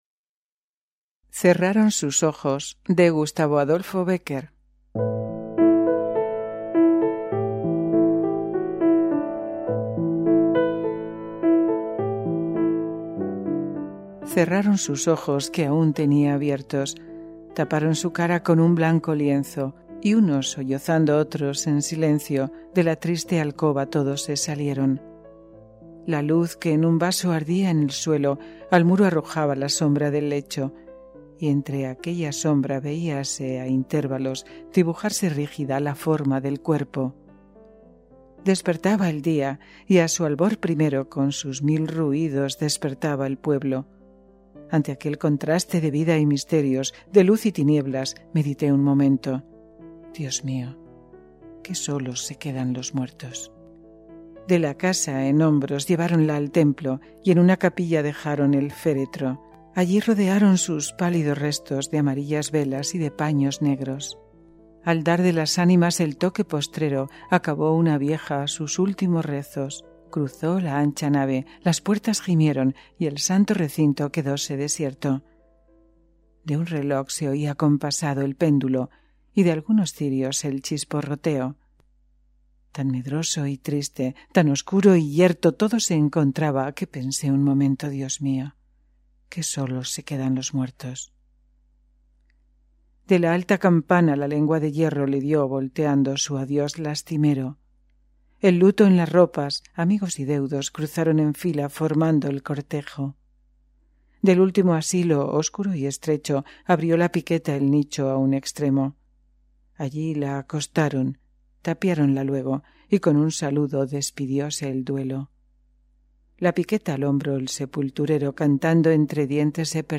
Audiolibros: Poesía
Rima
Audiolibro gratis